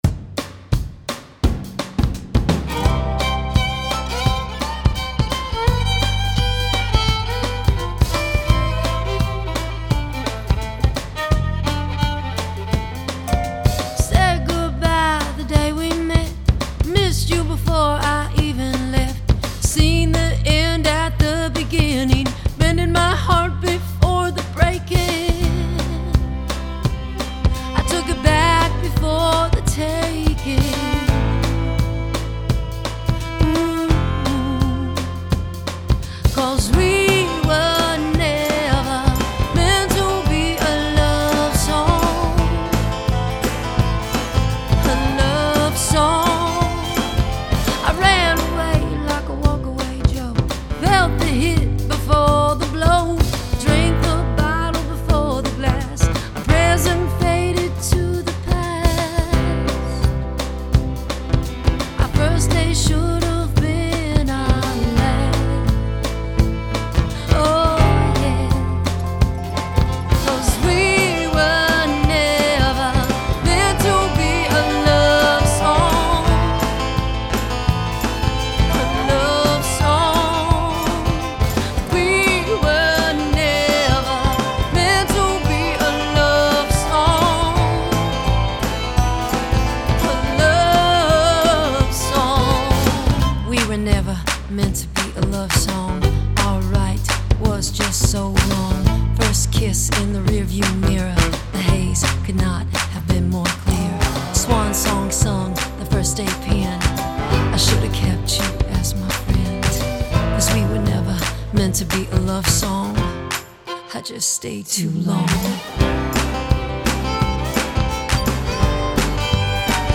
the Americana / country-folk-rock style
fine acoustic guitar work
boldly measured vocals throughout the album